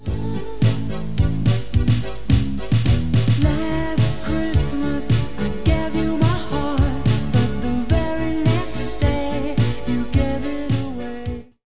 Campane - Babbo Natale Ho Ho Ho - Risata di Babbo Natale -